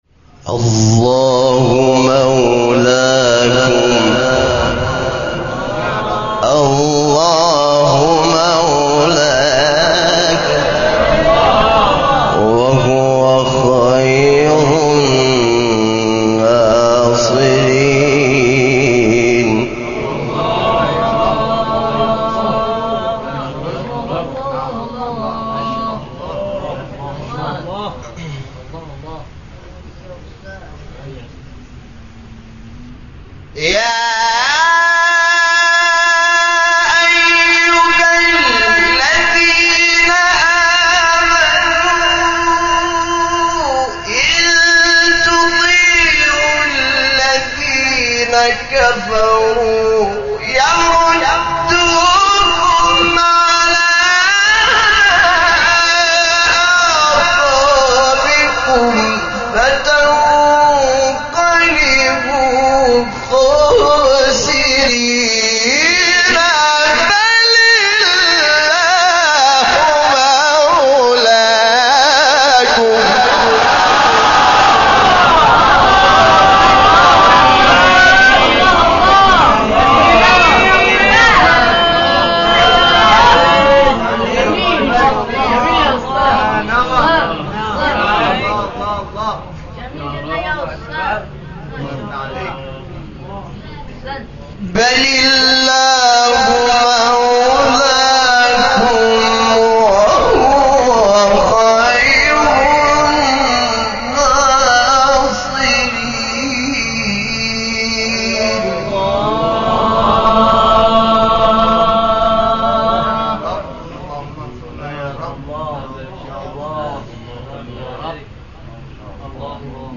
تلاوت بی نظیر سوره آل عمران